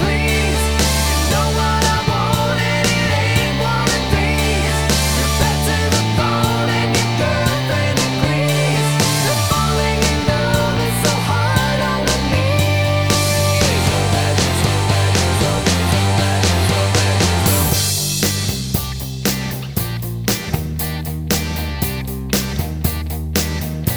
Minus Guitars Rock 3:06 Buy £1.50